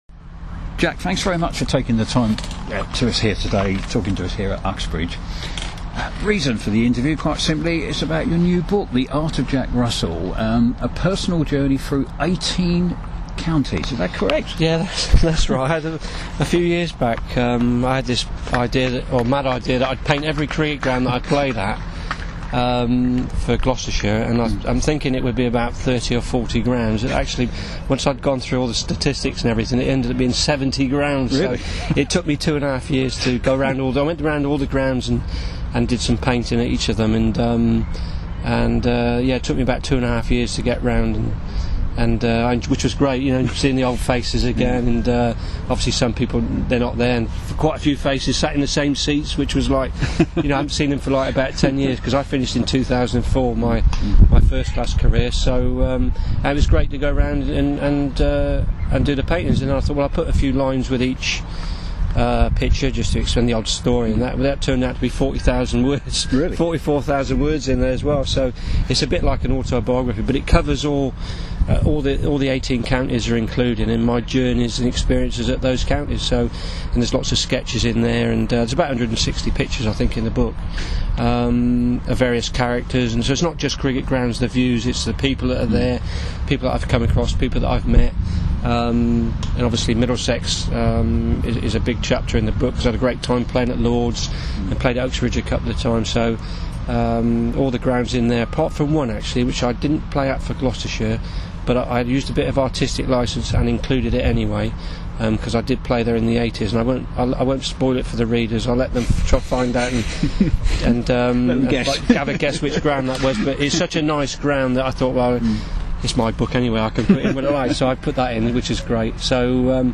Former England wicket keeper Jack Russell was at Uxbridge Cricket Club recently. I caught up with him to ask about his new book